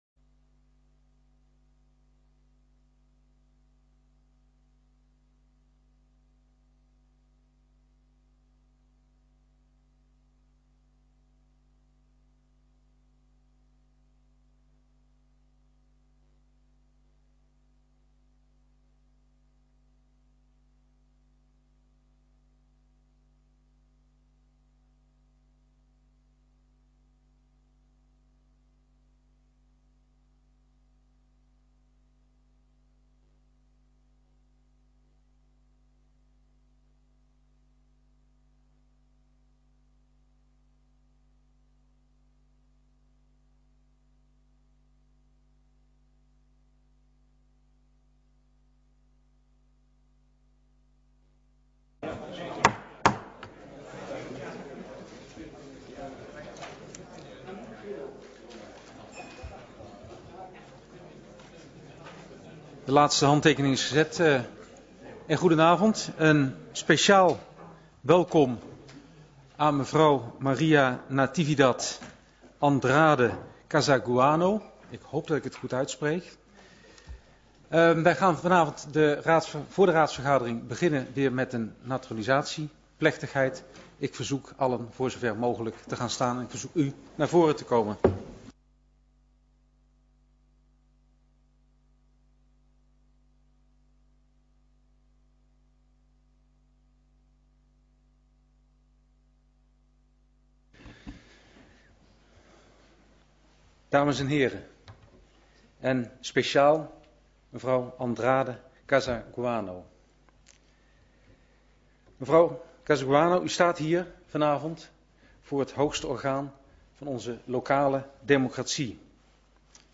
Gemeenteraad 25 januari 2011 20:00:00, Gemeente Tynaarlo
Locatie: Raadszaal